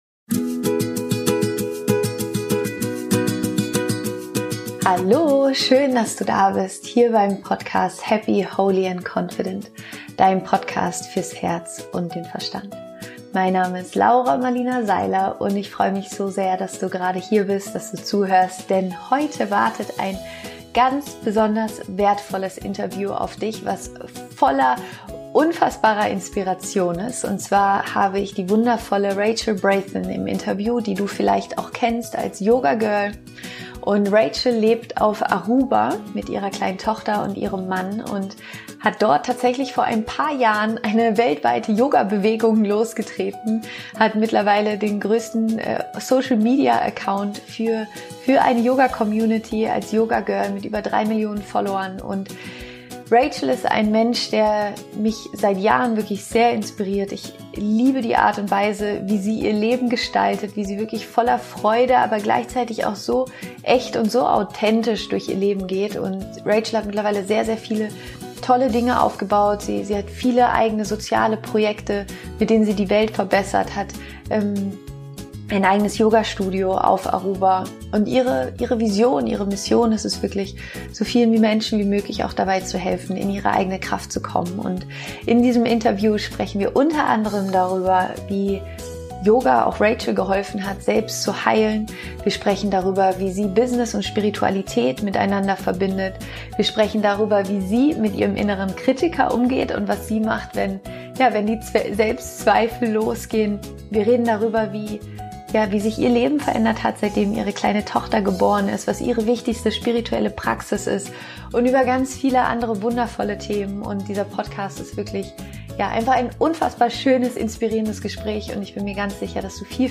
Wie du ein außergewöhnliches Leben voller Liebe erschaffst - Interview Special mit Rachel Brathen happy, holy & confident® Dein Podcast fürs Herz und den Verstand Download **Das Interview ist auf Englisch.